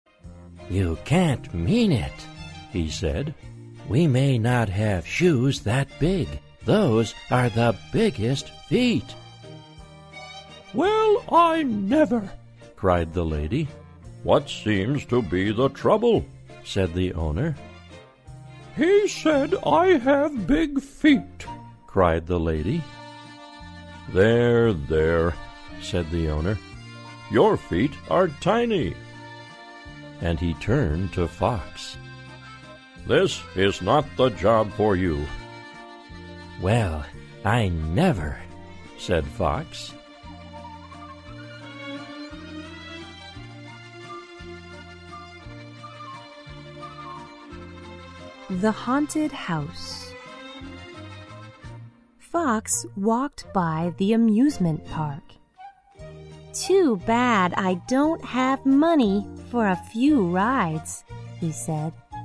在线英语听力室小狐外传 第77期:卖鞋的听力文件下载,《小狐外传》是双语有声读物下面的子栏目，非常适合英语学习爱好者进行细心品读。故事内容讲述了一个小男生在学校、家庭里的各种角色转换以及生活中的趣事。